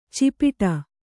♪ cipiṭa